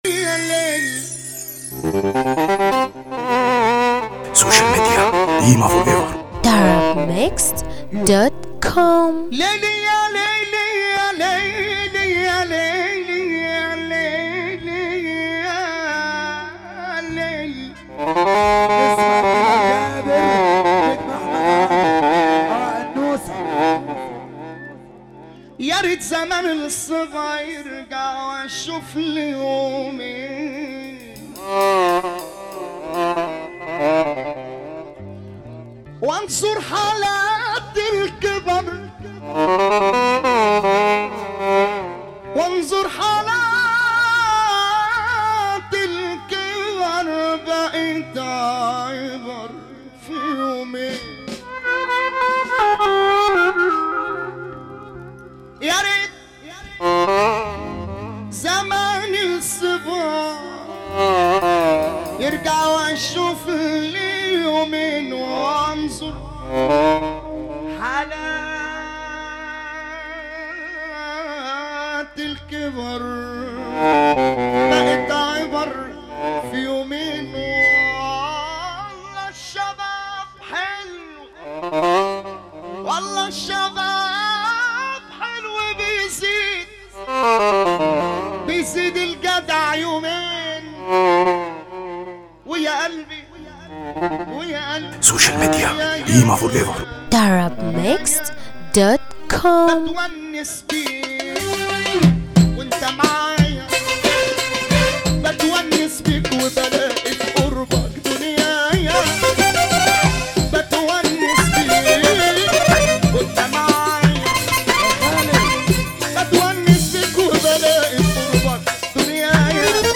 موال